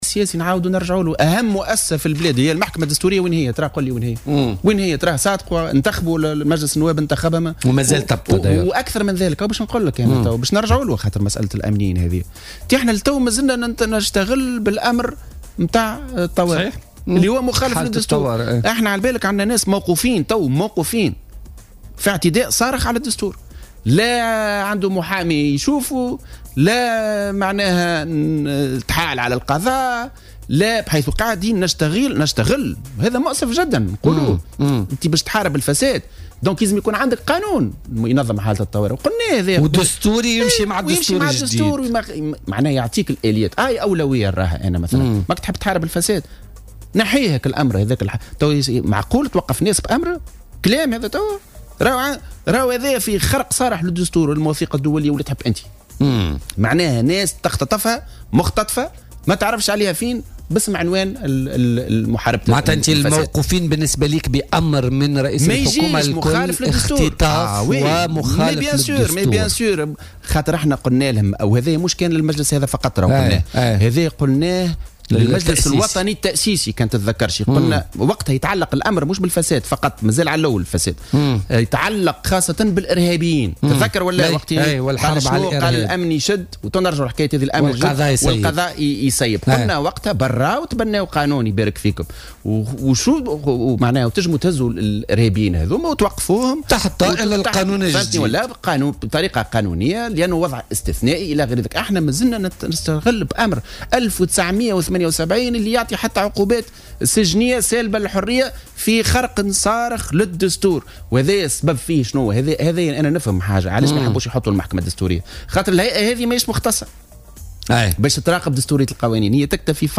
وقال ضيف "بوليتيكا" على "الجوهرة أف أم" إن هذا الاجراء اعتداء صارخ على الدستور ولا يمكن محاربة الفساد بمقتضى هذا الأمر الذي كان يفترض إلغاؤه.